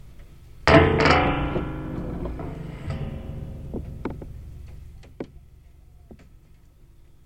门上的接触麦克风 " 门弹簧06
描述：用弹簧接触门上的麦克风
标签： 压电 金属 金属 博英 弹簧 鼻音
声道立体声